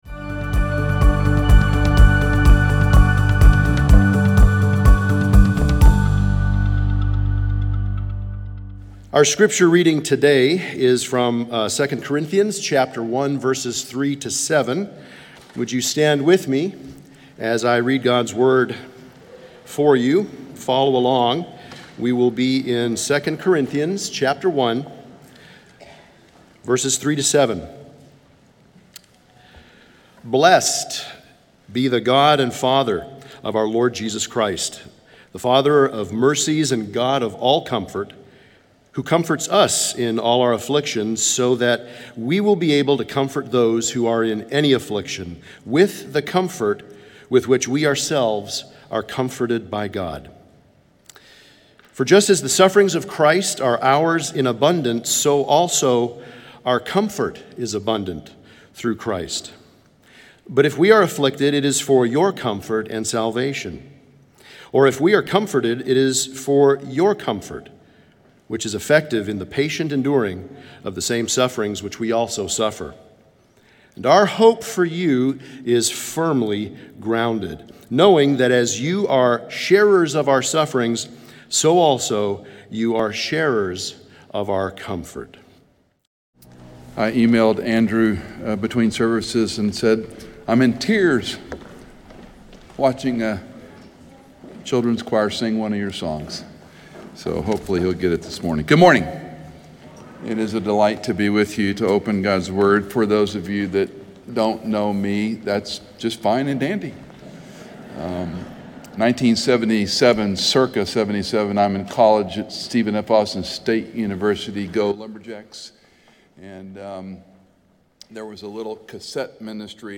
Listen to Message